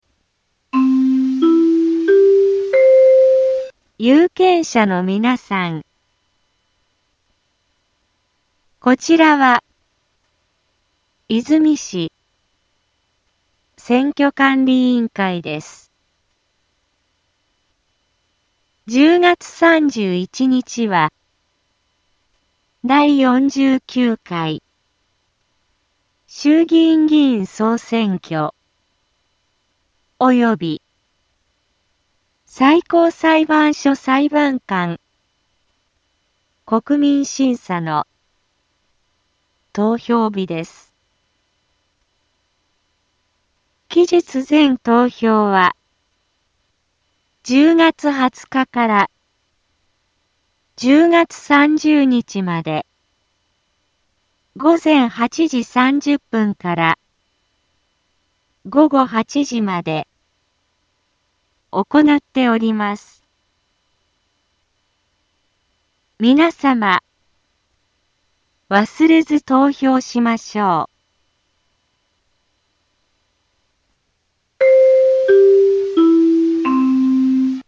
BO-SAI navi Back Home 災害情報 音声放送 再生 災害情報 カテゴリ：通常放送 住所：大阪府和泉市府中町２丁目７−５ インフォメーション：有権者のみなさん こちらは和泉市選挙管理委員会です １０月３１日は、第４９回衆議院議員総選挙及び最高裁判所裁判官国民審査の投票日です 期日前投票は、１０月２０日から１０月３０日まで午前８時３０分から午後８時まで行なっております 皆様、忘れず投票しましょう